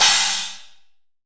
Index of /90_sSampleCDs/AKAI S6000 CD-ROM - Volume 3/Crash_Cymbal2/SHORT_DECAY_CYMBAL